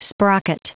Prononciation du mot sprocket en anglais (fichier audio)
Prononciation du mot : sprocket